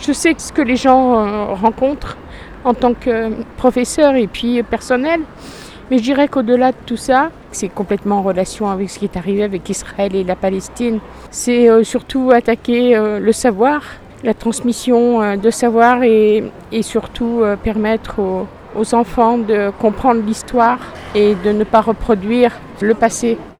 ITC Retraitée 3-Rassemblement hommage Dominique Bernard .wav